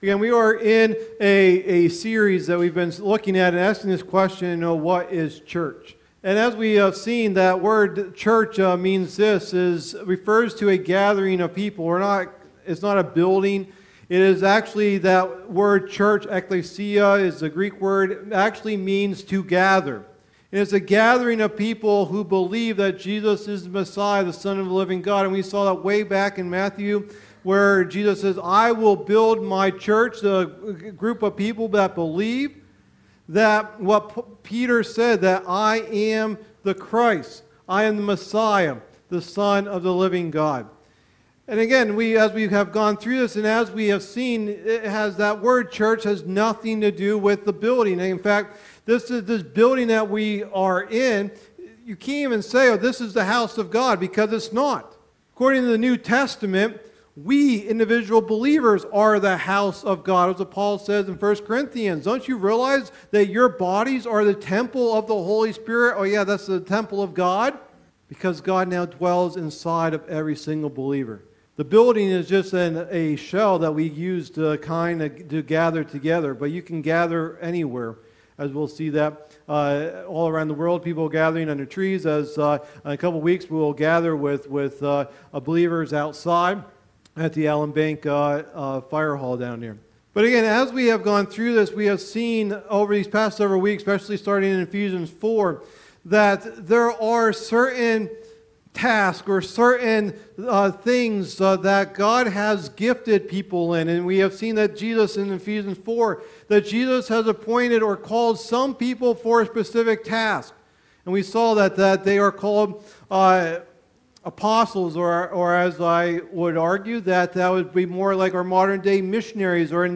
Message #7 of the "What is Church?" teaching series